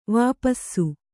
♪ vāpassu